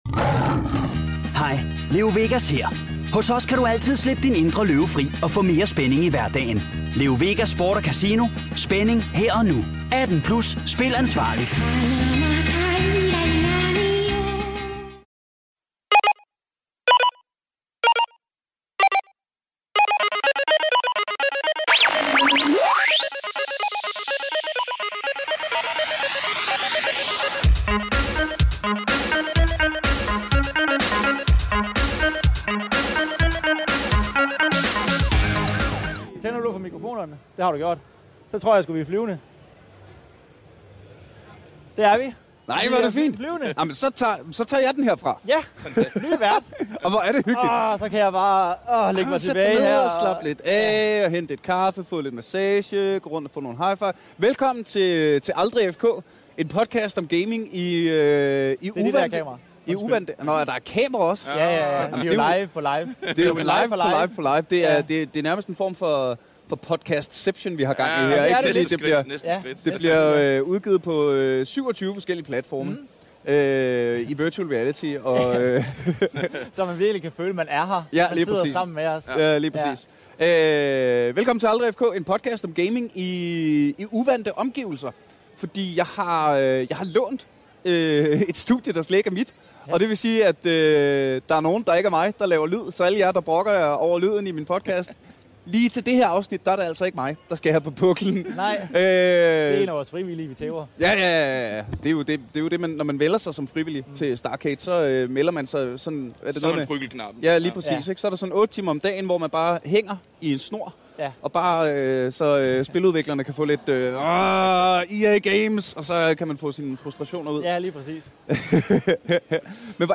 Vi er live fra Starcade Festival i Aalborg. Vi har lånt et studie og inviteret 2 af de mennesker i landet, der ved allermest om spiludvikler-scenen i Danmark!